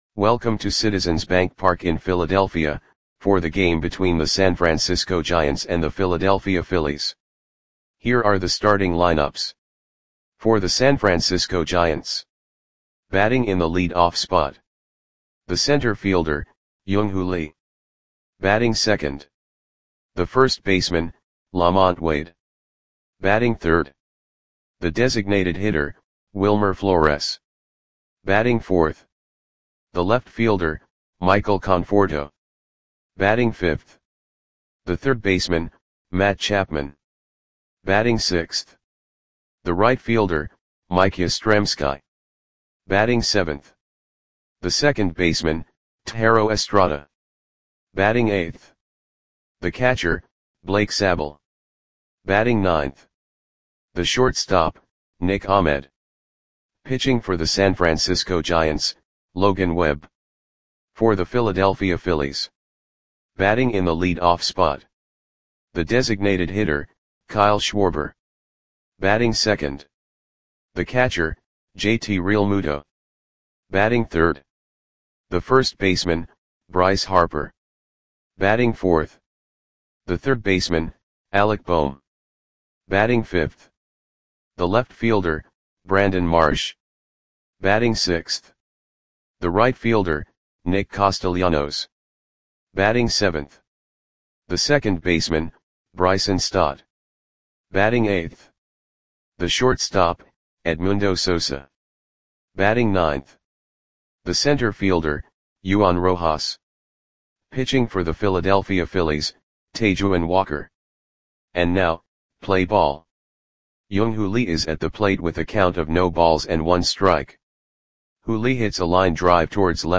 Audio Play-by-Play for Philadelphia Phillies on May 5, 2024
Click the button below to listen to the audio play-by-play.